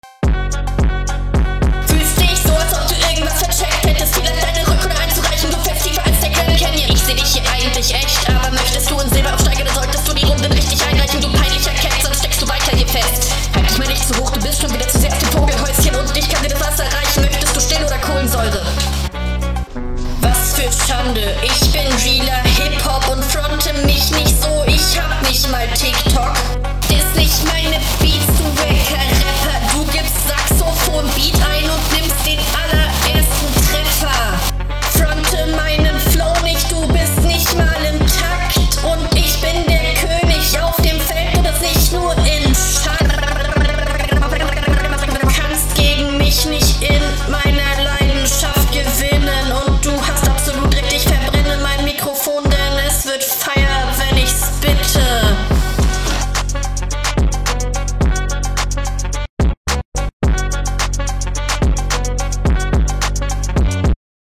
beat gecutted, gewonnen +punkte weil kein tiktok kreativität eigentlich 10, aber schiebe halt so (L) …